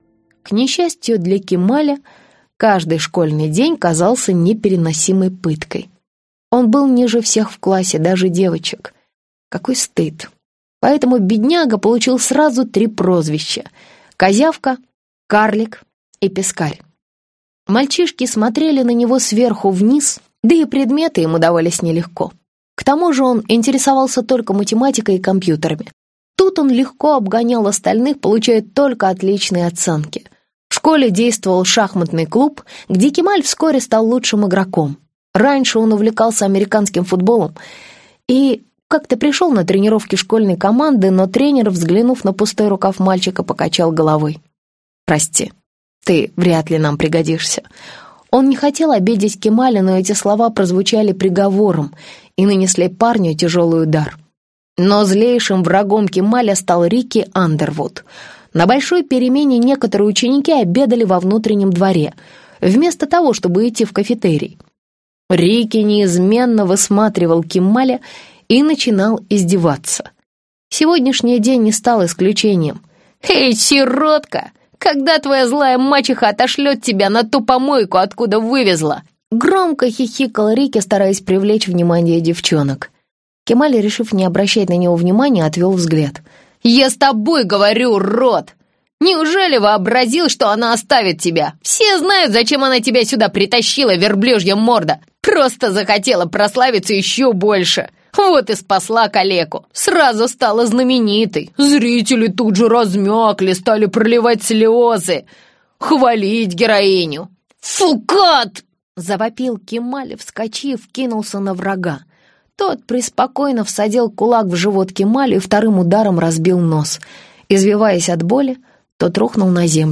Аудиокнига Рухнувшие небеса - купить, скачать и слушать онлайн | КнигоПоиск